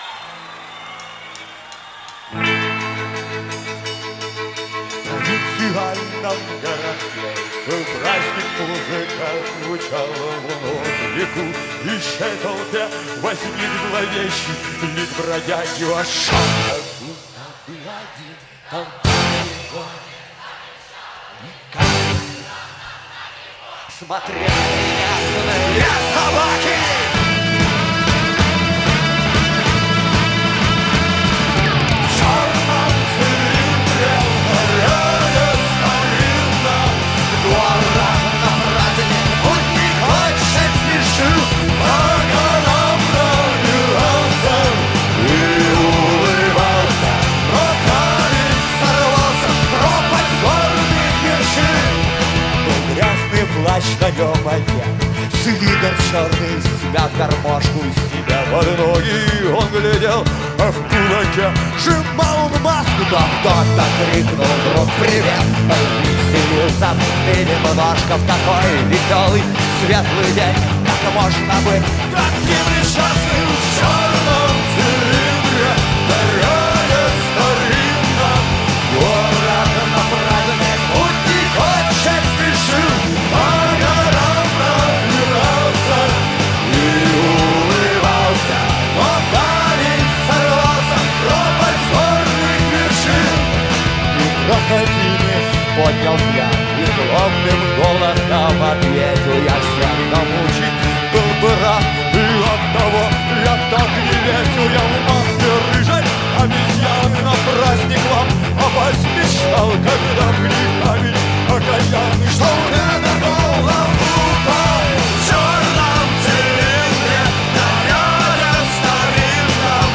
Жанр: Метал / Рок